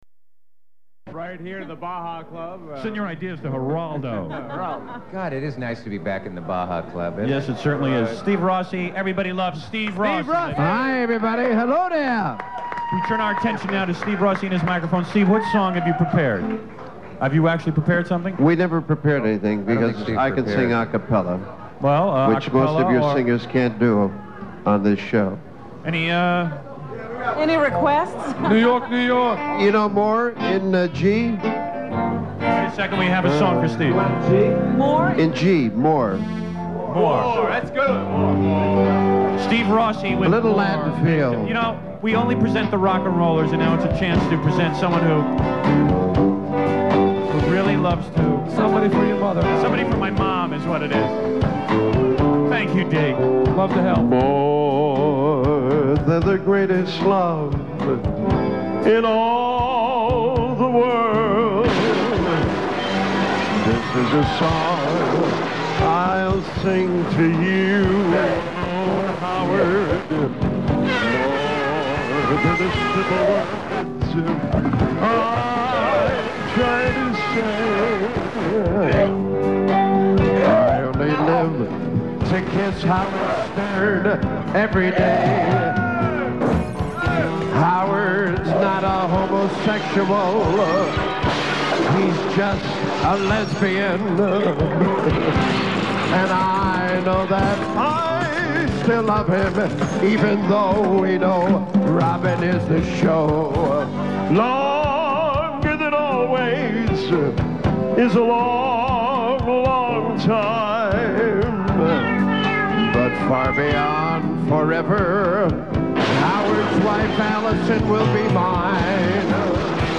So here are some airchecks of a few of those performances.